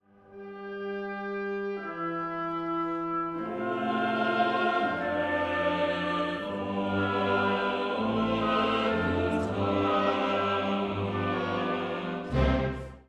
The scoring is for two violins, bass, and organ continuo with bassoons doubling the bass, two trumpets, timpani, two horns, two oboes, and 3 trombones doubling the alto, tenor, and bass vocal parts.